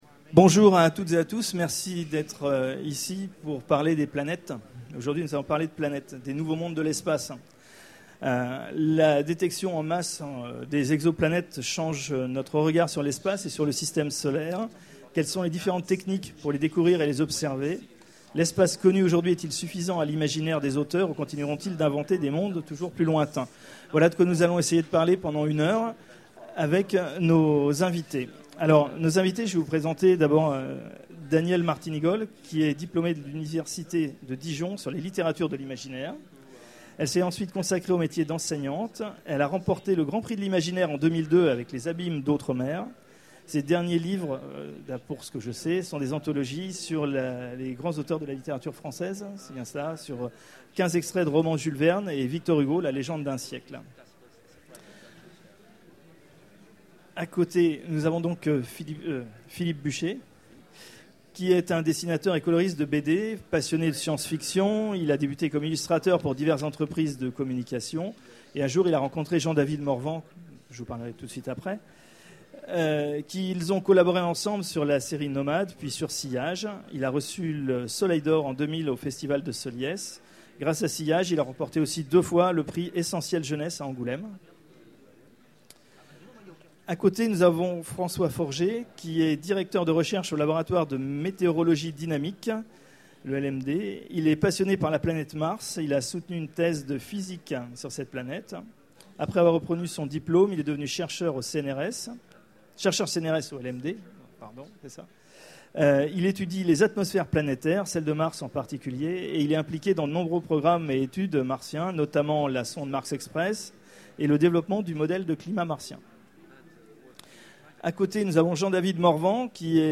Utopiales 12 : Conférence Les nouveaux mondes de l’espace